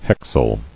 [hex·yl]